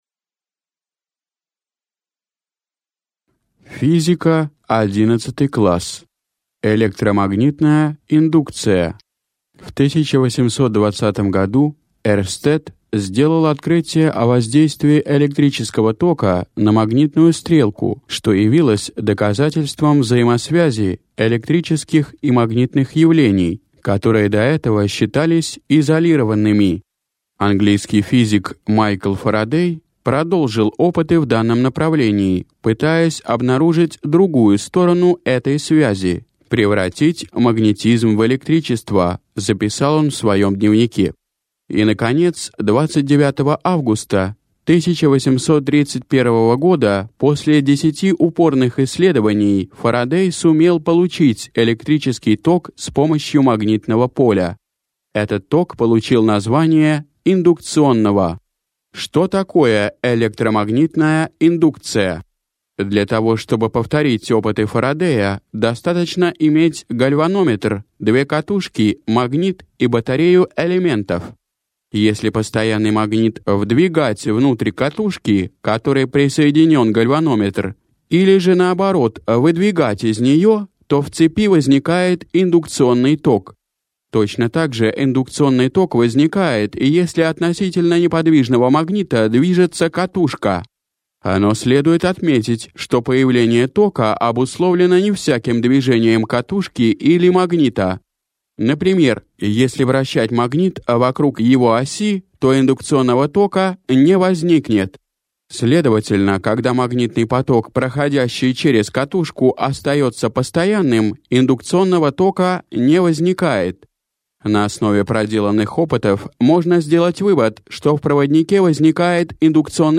Аудиокнига 11 класс. Физика | Библиотека аудиокниг